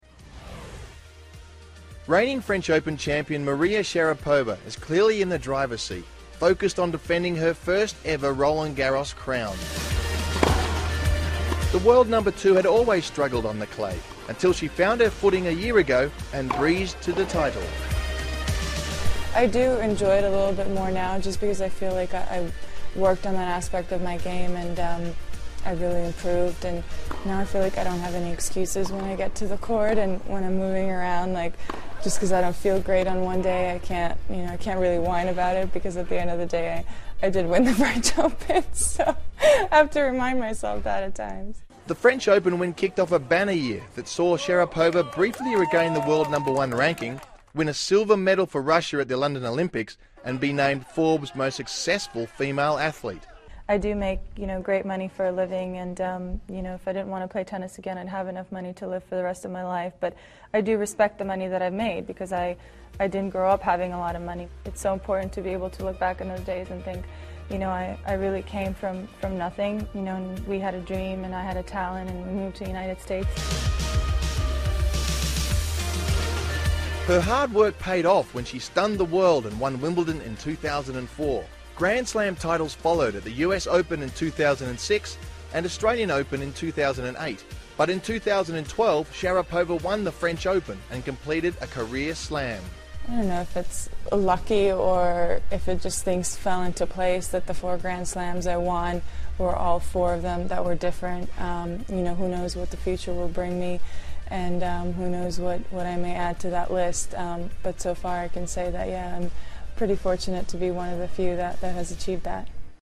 访谈录 2013-05-21&05-23 网坛美女莎拉波娃专访 听力文件下载—在线英语听力室